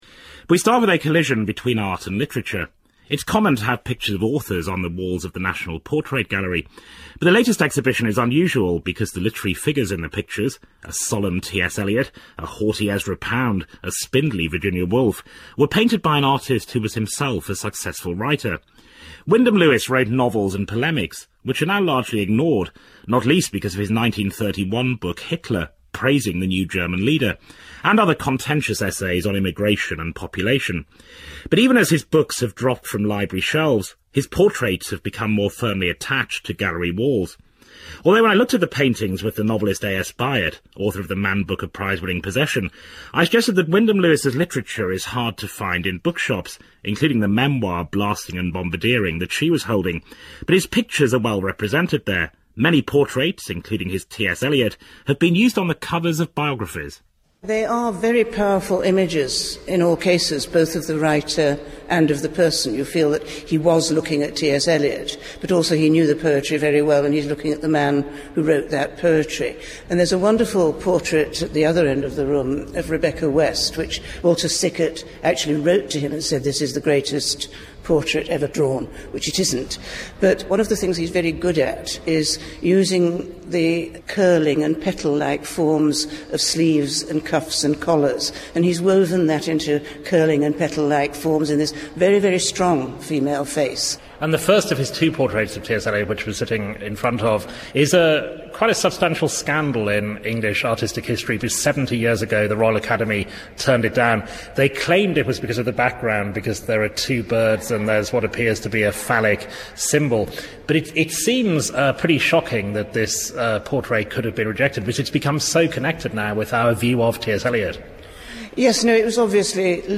Listen to A.S. Byatt talk to Mark Lawson: